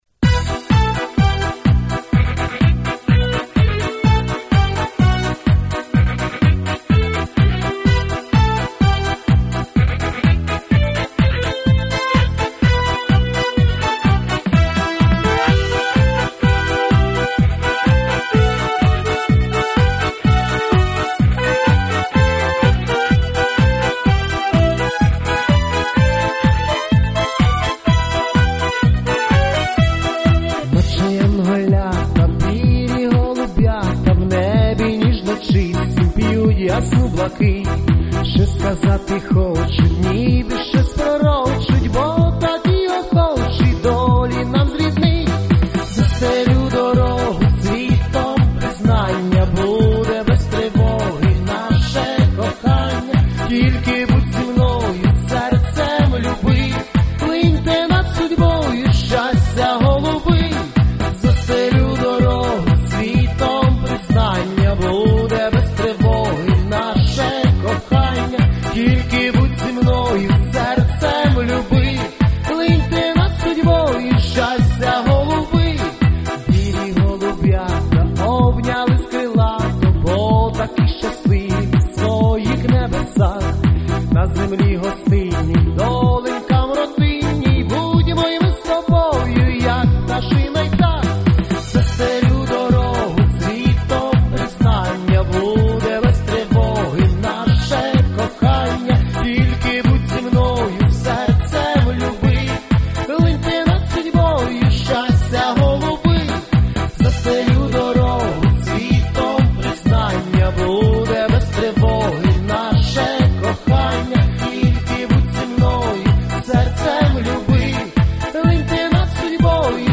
ЩАСТЯ ГОЛУБИ ( весільна пісня)
Рубрика: Поезія, Авторська пісня